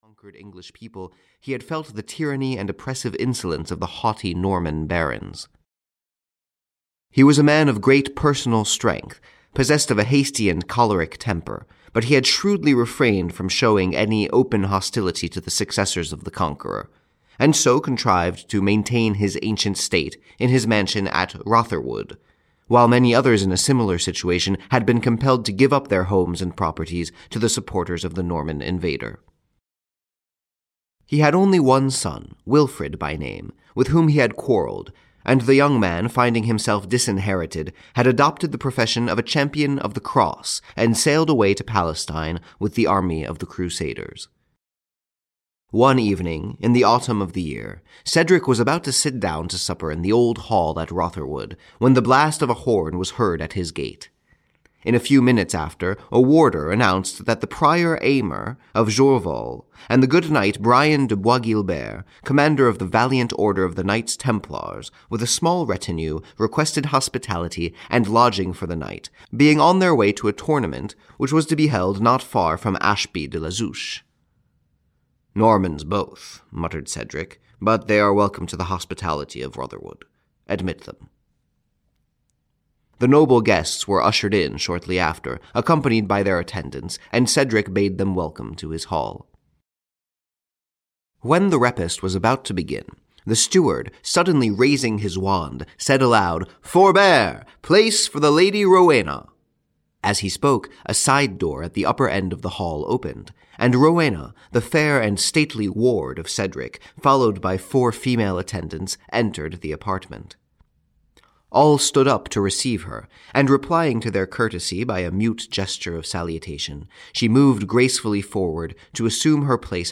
Ivanhoe (EN) audiokniha
Ukázka z knihy